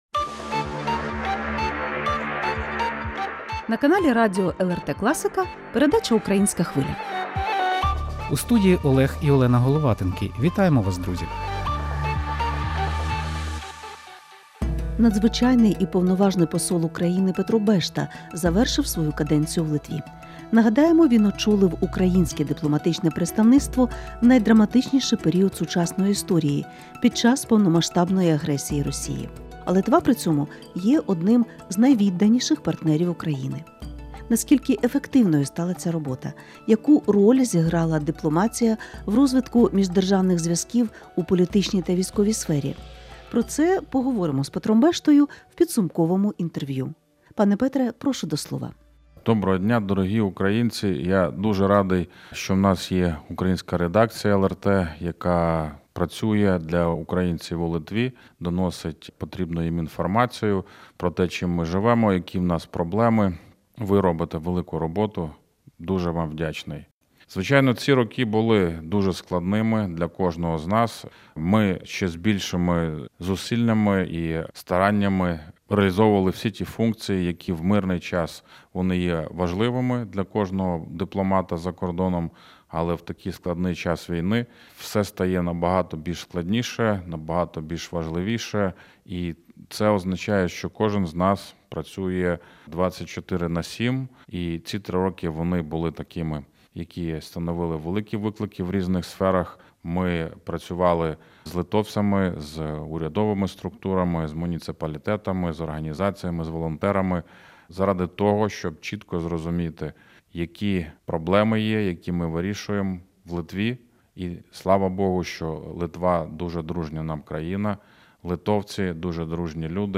Підсумкове інтерв'ю Надзвичайного і Повноважного посла України в Литві Петра Бешти